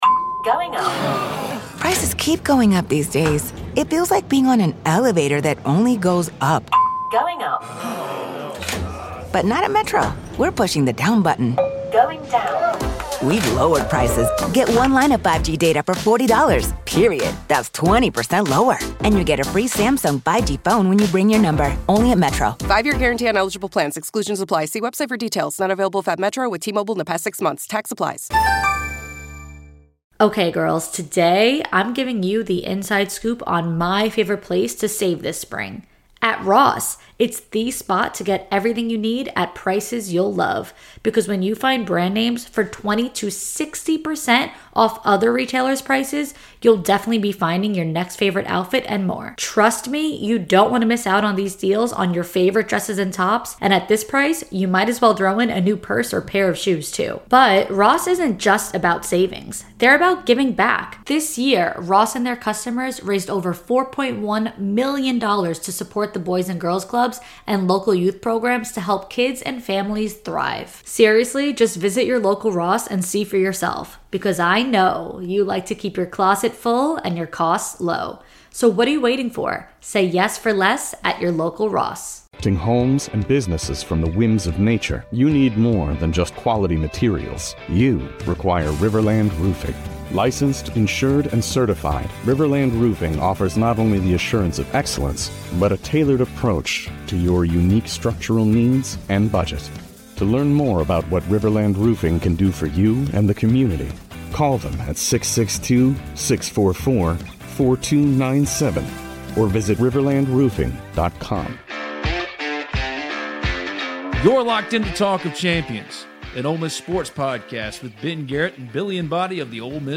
On this LIVE Talk of Champions